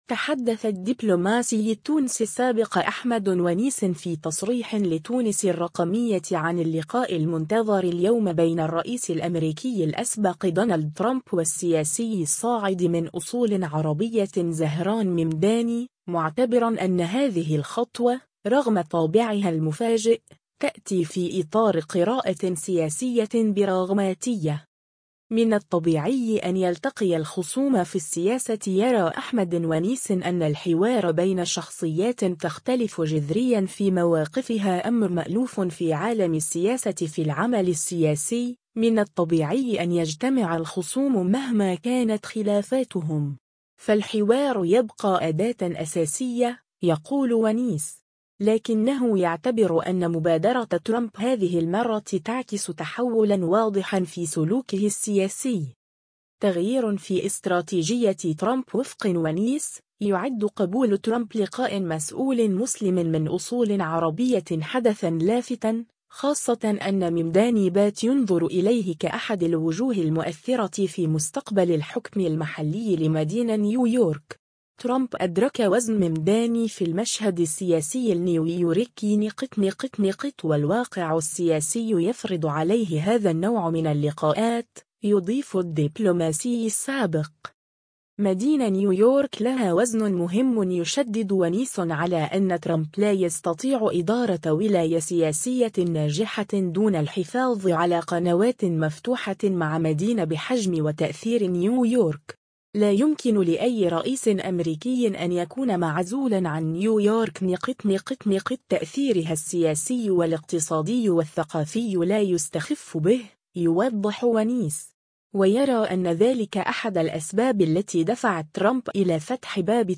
تحدّث الدبلوماسي التونسي السابق أحمد ونيس في تصريح لتونس الرقمية عن اللقاء المنتظر اليوم بين الرئيس الأمريكي الأسبق دونالد ترامب والسياسي الصاعد من أصول عربية زهران ممداني، معتبراً أن هذه الخطوة، رغم طابعها المفاجئ، تأتي في إطار قراءة سياسية براغماتية.